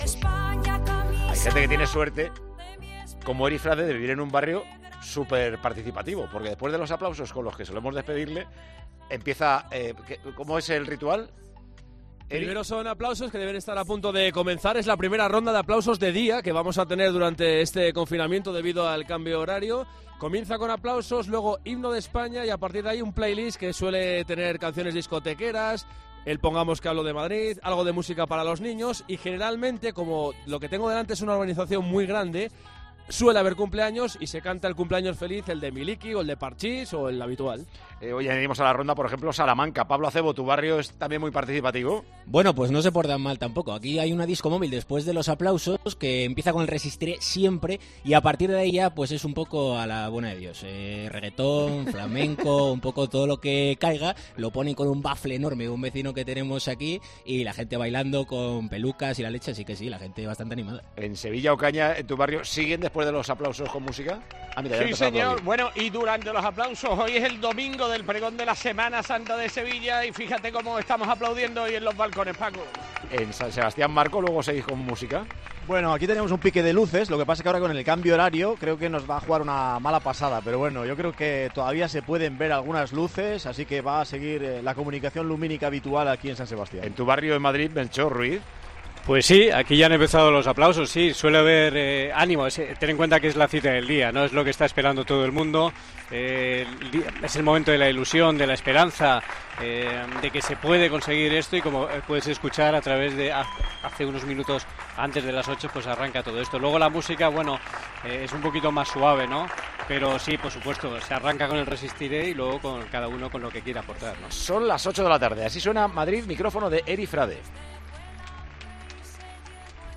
Primera ronda de aplausos con horario de verano
AUDIO: Quizás porque todavía hay luz natural, pero mucha gente ha salido a aplaudir este domingo a sus ventanas.